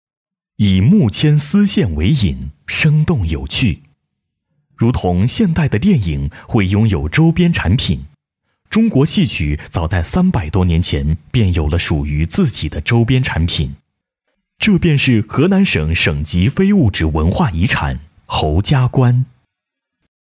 Chinese Mandarin male voice over talent